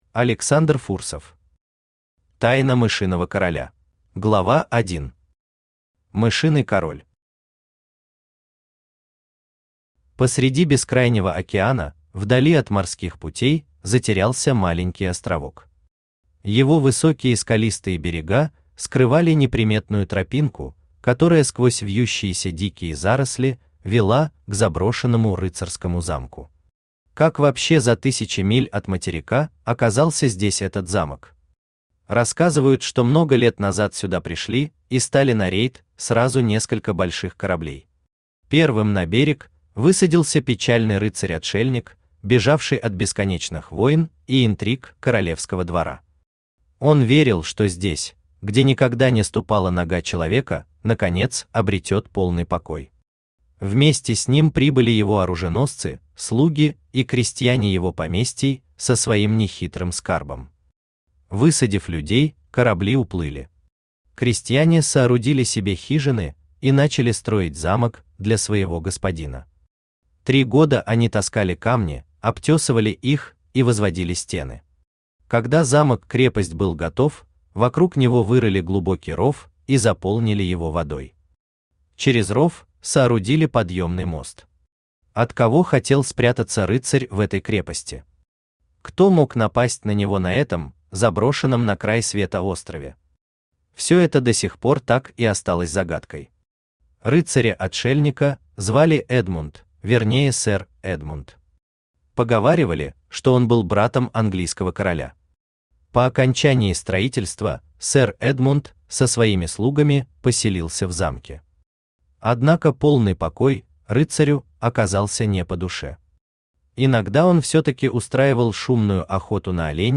Aудиокнига Тайна мышиного короля Автор Александр Иванович Фурсов Читает аудиокнигу Авточтец ЛитРес.